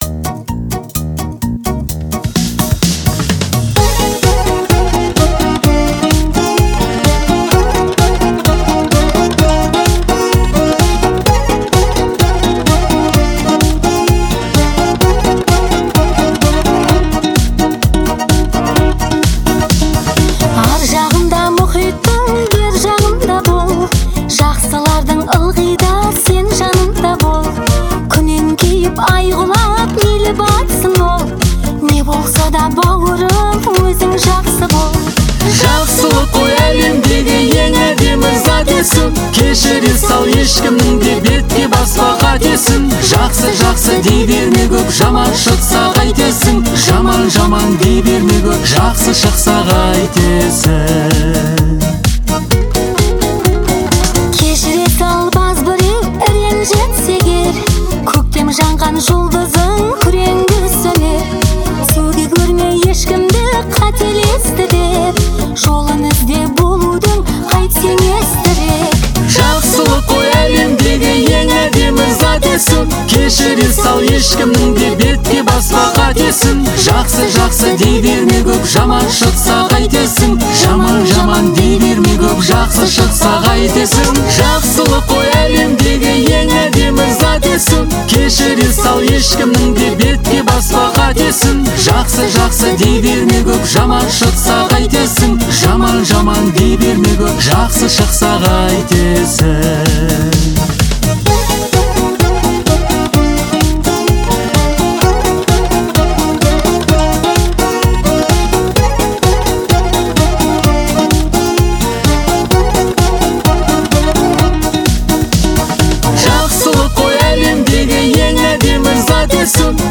это романтический дуэт в жанре казахской поп-музыки.